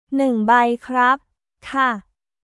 ヌン バイ クラップ／カ